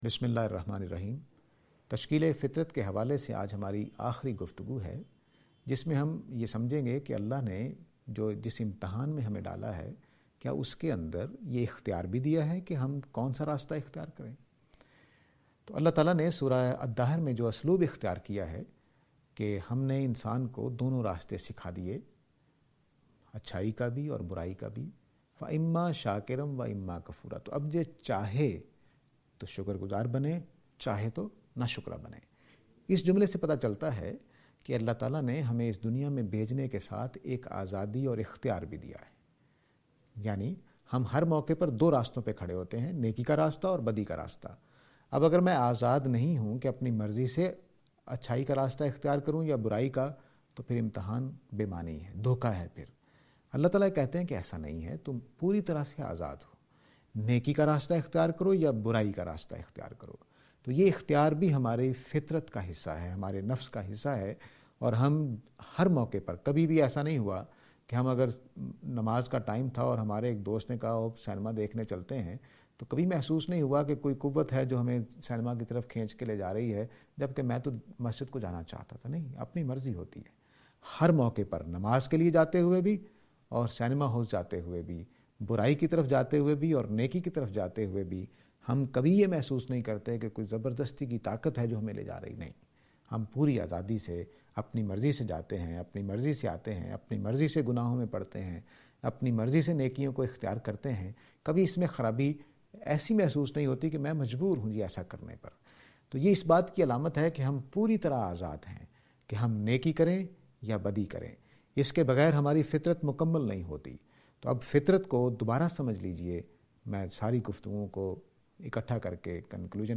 This lecture is and attempt to answer the question "Construction of Nature (Free Will)".